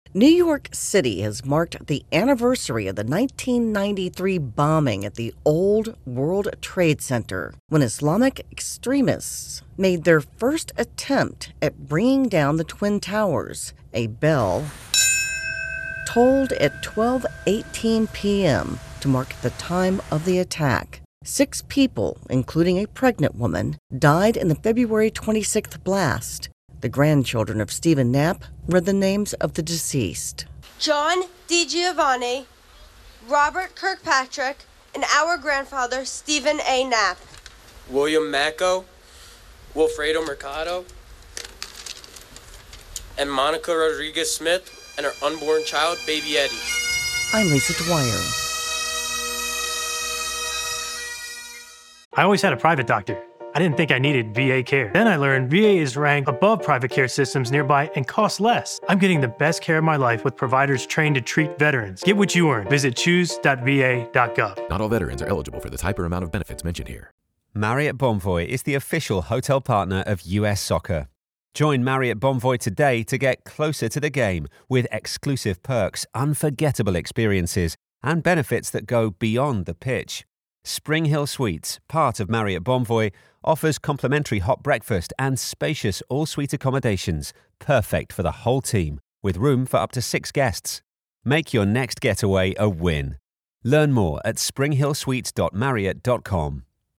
Bell tolls as New York marks the anniversary of the 1993 World Trade Center bombing